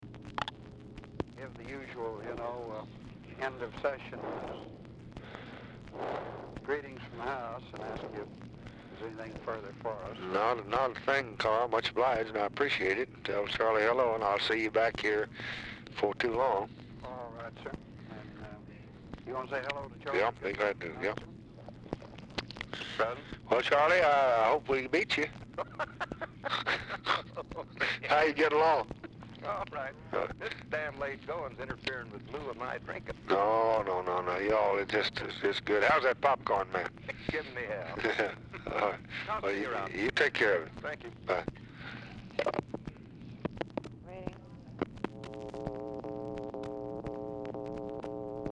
Telephone conversation # 5827, sound recording, LBJ and CARL ALBERT, 10/2/1964, 7:40PM | Discover LBJ
Format Dictation belt
Location Of Speaker 1 Oval Office or unknown location
Specific Item Type Telephone conversation Subject Condolences And Greetings Congressional Relations